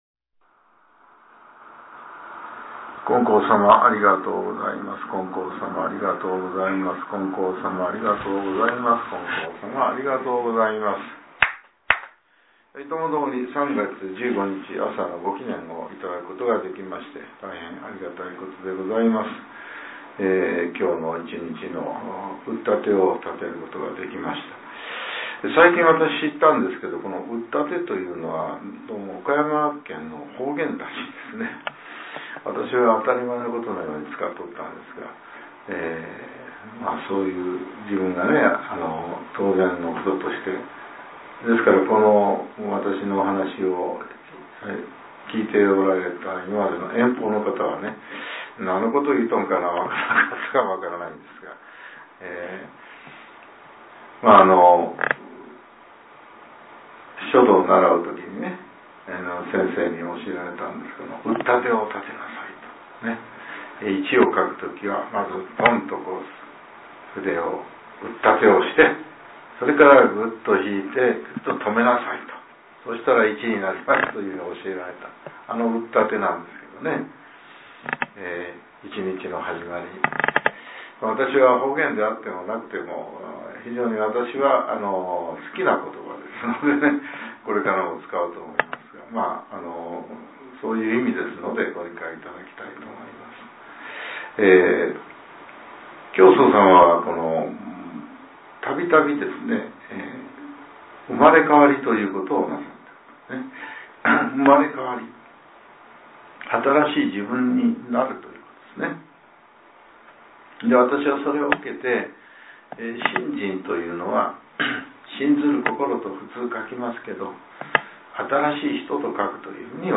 令和７年３月１５日（朝）のお話が、音声ブログとして更新されています。